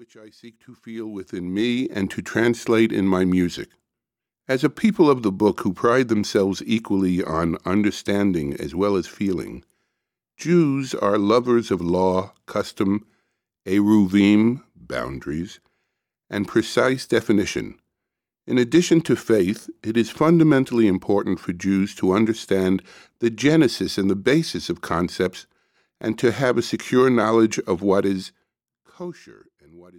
(Audiobook)